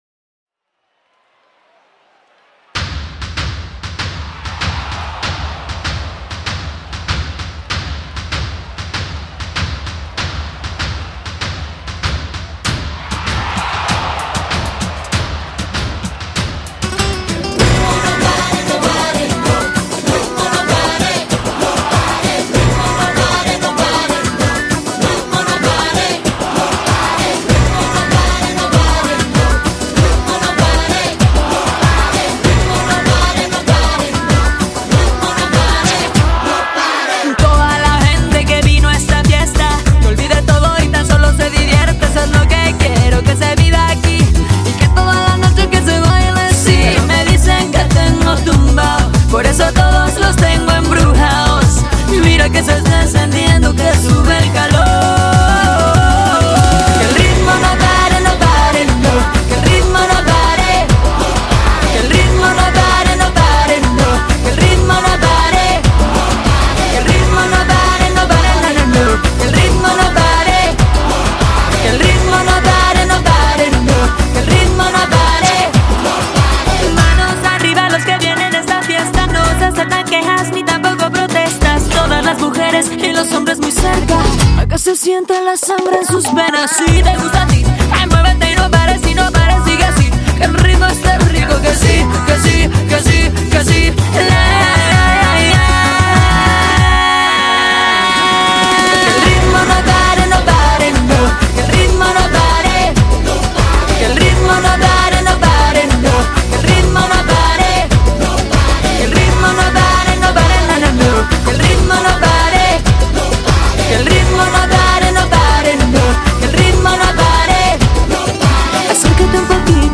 12位传奇拉丁巨星音乐盛宴。